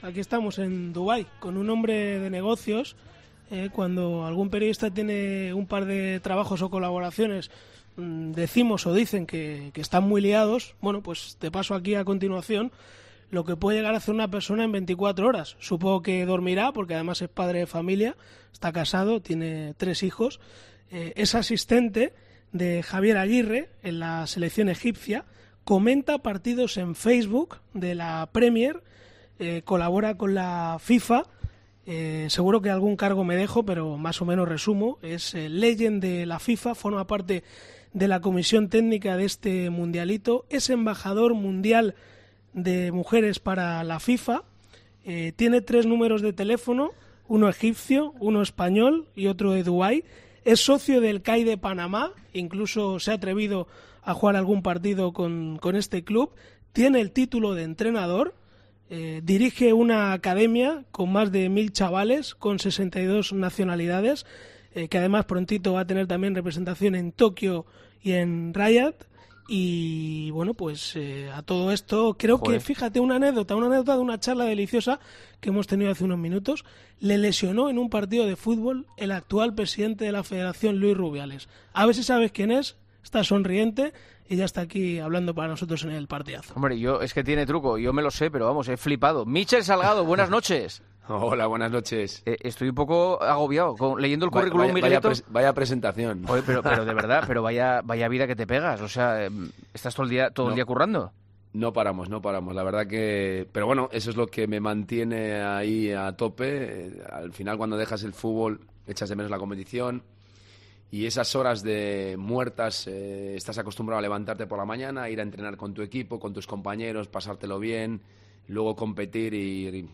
Allí está Míchel Salgado, ex futbolista del conjunto blanco, asistente de Javier Aguirre con la selección egipcia, es comentarista de fútbol, colabora con la FIFA, vive de forma habitual en Dubai y fue protagonista este lunes en El Partidazo de COPE, donde habló de fútbol con Juanma Castaño.